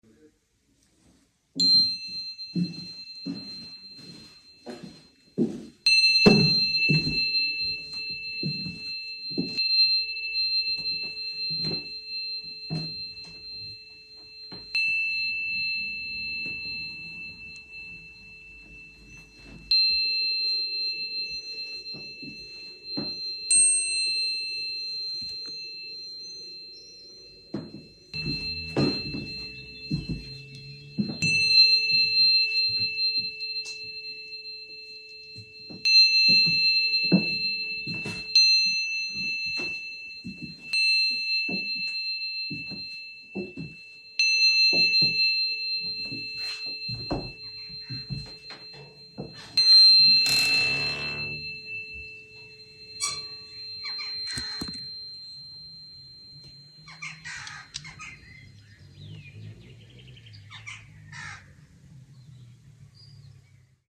✨ AU DETOUR DU MONT Chambres d'hôtes near Mont Saint-Michel ✨🇫🇷 No matter which country I visit, the first thing I do when I enter a hotel room is cleanse the space. This time, I used ✨432Hz – the frequency of the universe and sacred patterns ✨4160Hz – the frequency of purification and the stairway to heaven.